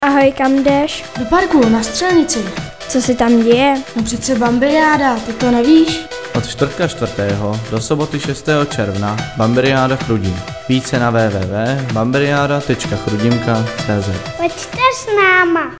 Rádiový spot chrudimské Bambiriády